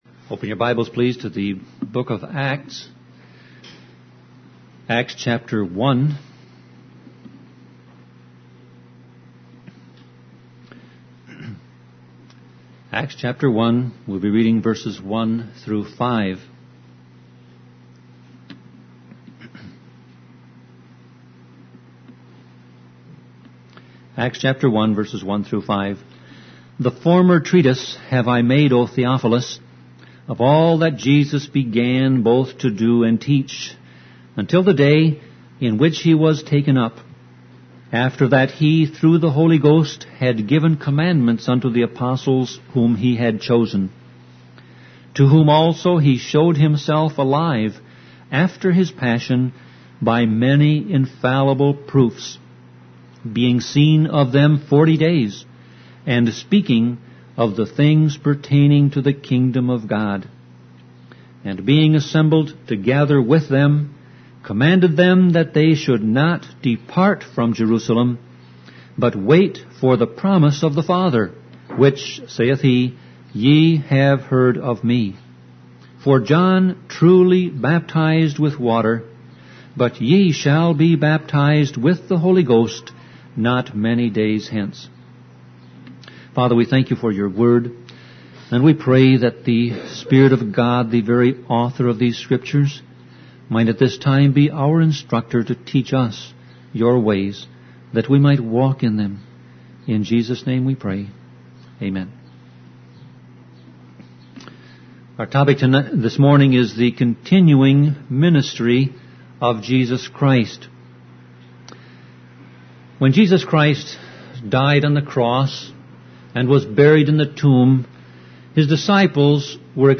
Sermon Audio Passage: Acts 1:1-5 Service Type